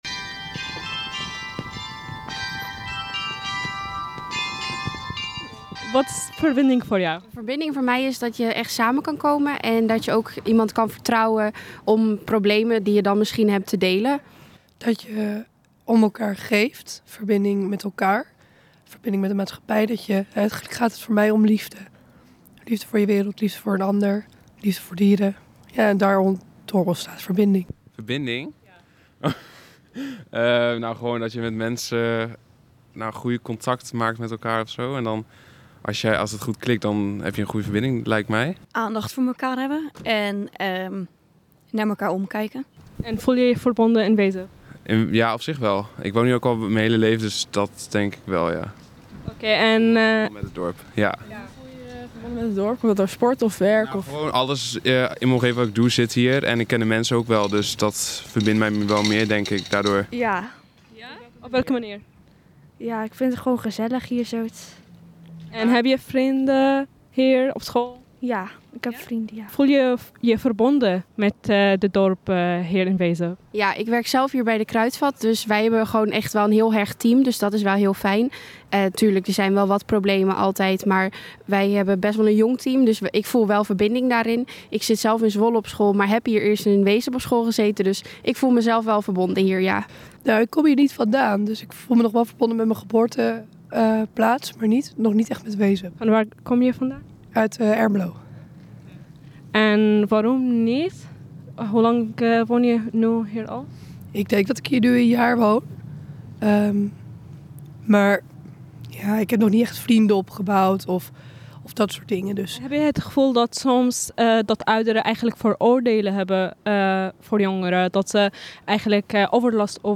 Vox pop – verbinding in Wezep
Uit de gesprekken die wij, als redactie INWezep, met mensen hebben gevoerd, is naar voren gekomen dat er vooroordelen bestaan over jongeren en dat jongeren zich door groepsdruk anders kunnen gedragen. Daarom zijn wij naar het centrum van Wezep gegaan om hierover met jongeren te spreken, naar hun mening te vragen en te ontdekken wat verbinding voor hen betekent.